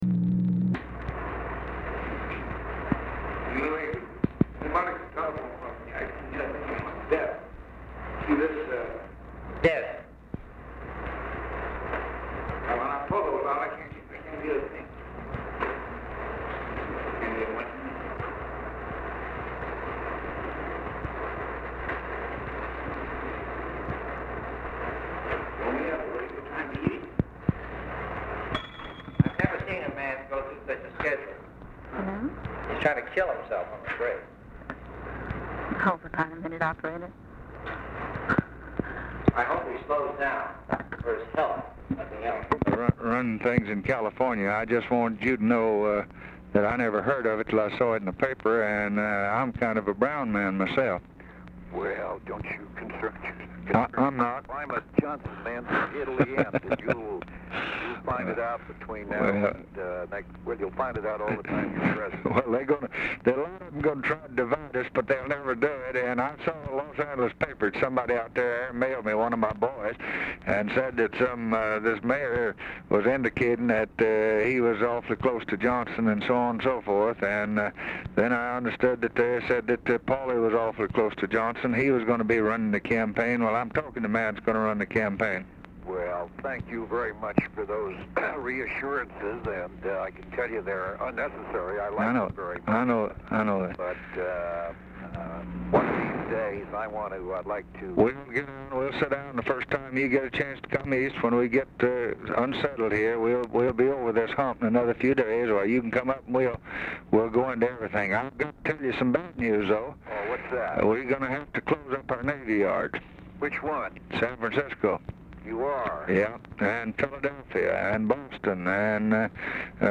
POOR SOUND QUALITY; BARELY AUDIBLE OFFICE CONVERSATION ABOUT LBJ'S HEAVY SCHEDULE PRECEDES CALL; BROWN ON HOLD 0:50
Format Dictation belt
Specific Item Type Telephone conversation